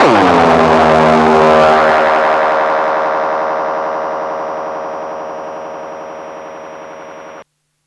Bass x-tra 2.83.wav